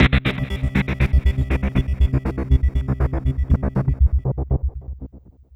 SYNTH CLO0DL.wav